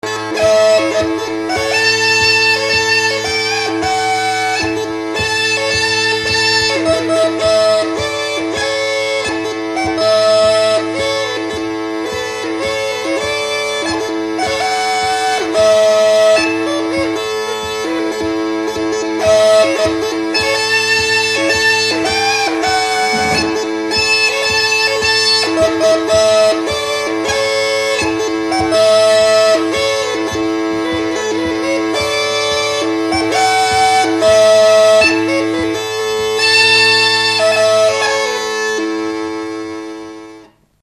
Dallampélda: Hangszeres felvétel
Felföld - Nógrád vm. - Romhány
duda Műfaj: Lassú csárdás Gyűjtő